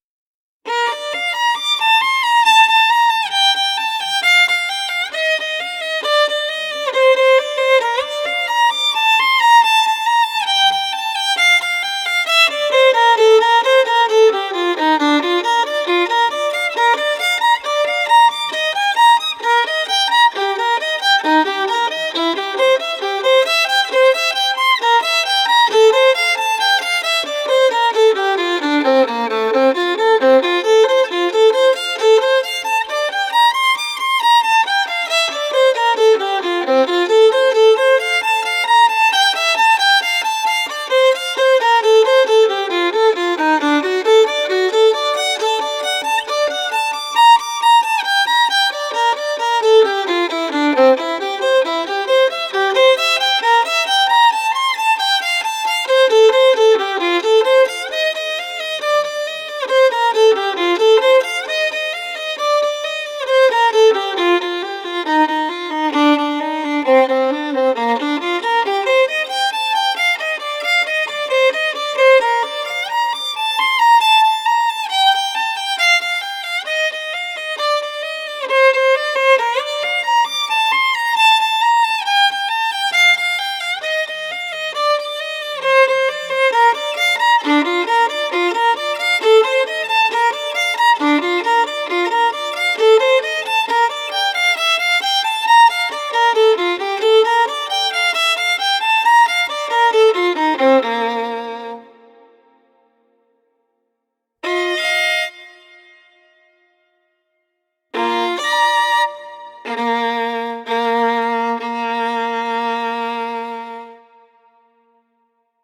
Una selección de estudios del libro 60 estudios para violín op. 45 del violinista y pedagogo alemán F. Wolfhart, en la edición de Frigyes Sándor.